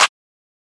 aw_clap_sizzle.wav